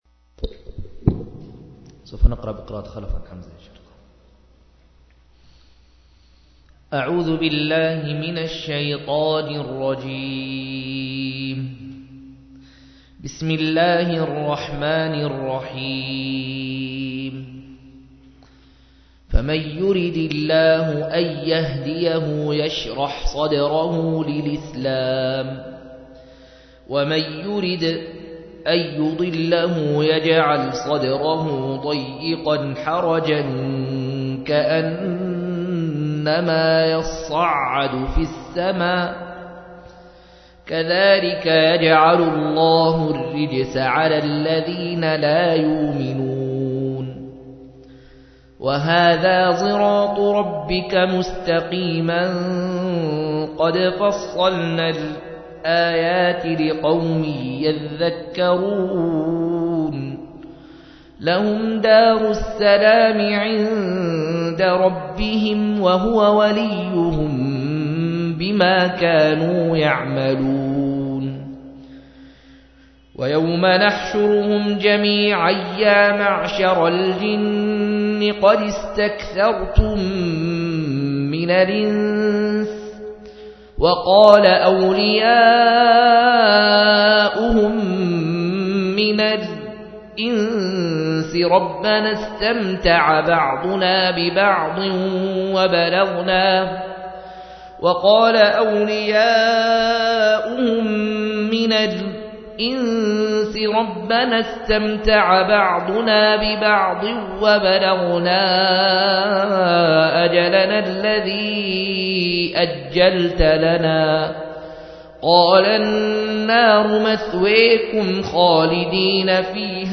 138- عمدة التفسير عن الحافظ ابن كثير رحمه الله للعلامة أحمد شاكر رحمه الله – قراءة وتعليق –